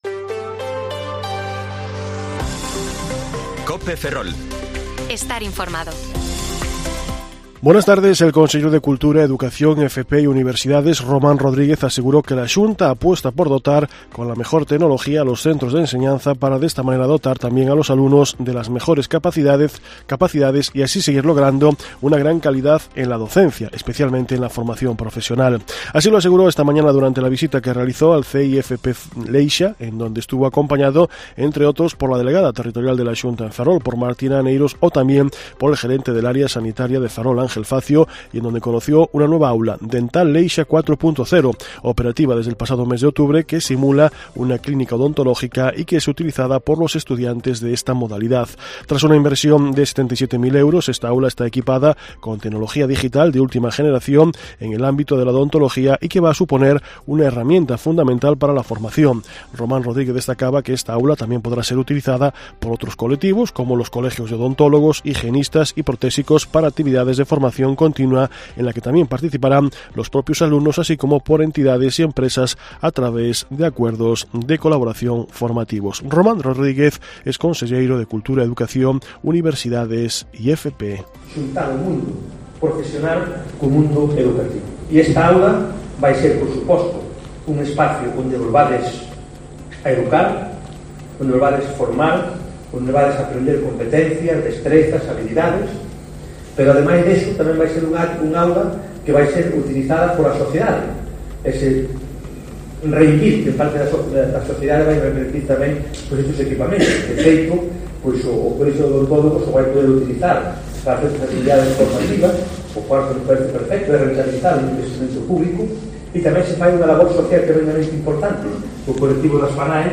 Informativo Mediodía COPE Ferrol 14/12/2022 (De 14,20 a 14,30 horas)